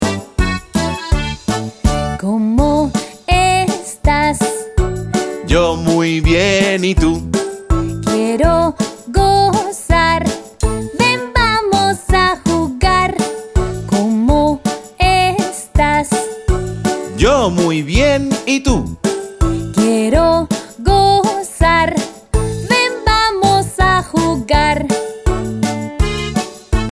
This playful Spanish children’s song
This light-hearted song